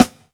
• Solid Steel Snare Drum Sound B Key 122.wav
Royality free snare one shot tuned to the B note. Loudest frequency: 1180Hz
solid-steel-snare-drum-sound-b-key-122-XD4.wav